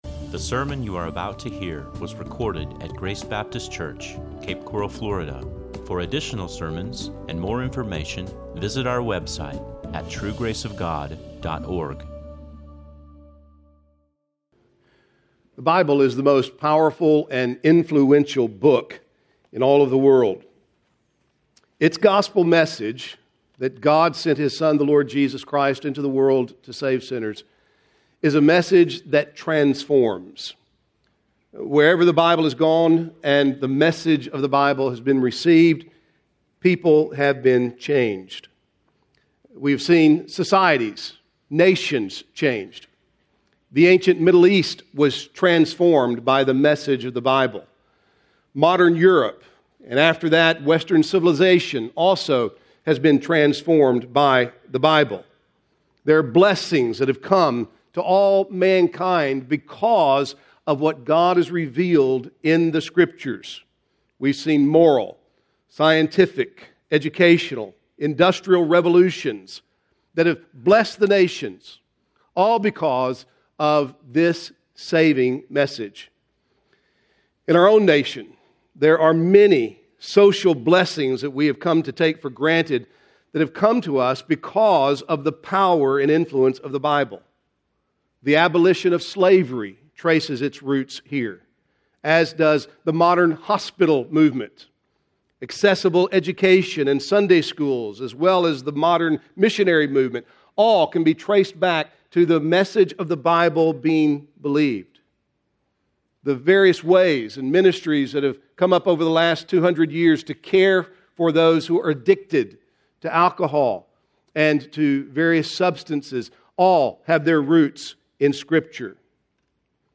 This is a sermon on Philemon 1:1-25.